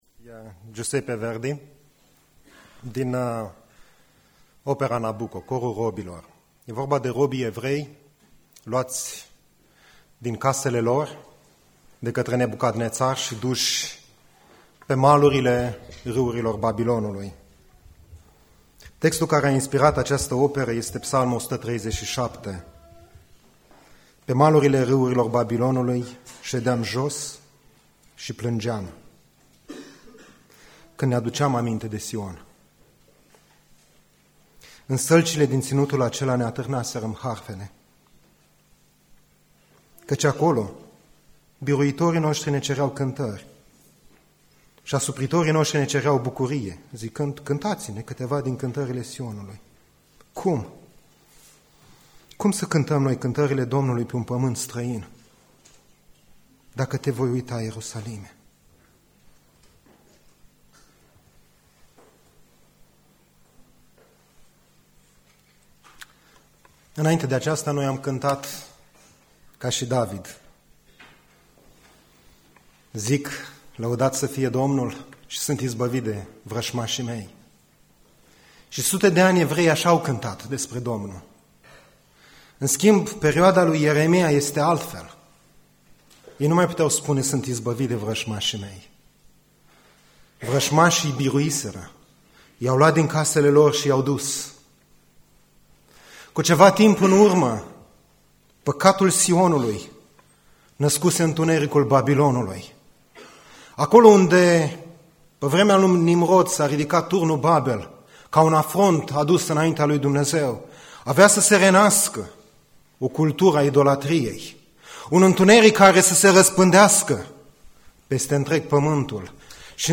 Predica Exegeza- Ieremia 26-27